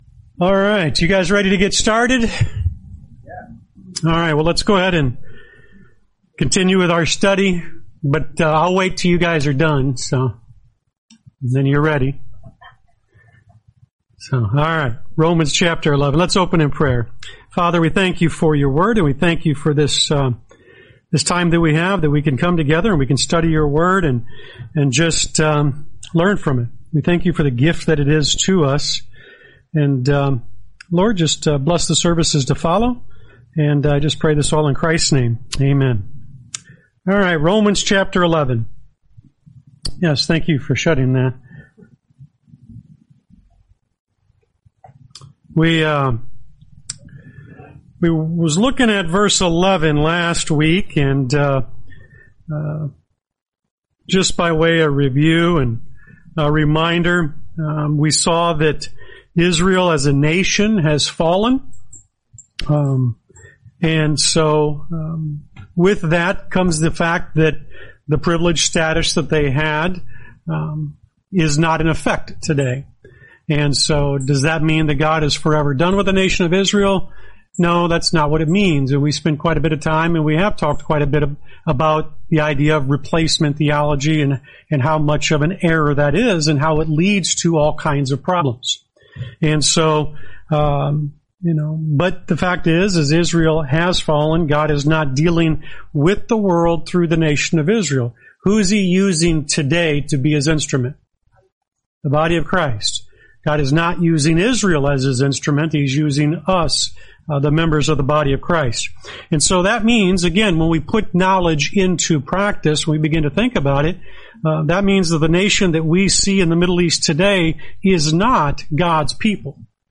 Lesson 71: Romans 11:11-13